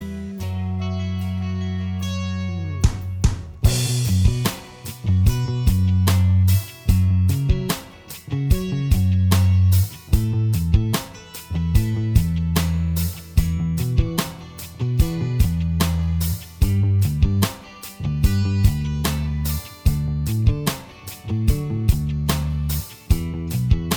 Cut Down Soft Rock 4:52 Buy £1.50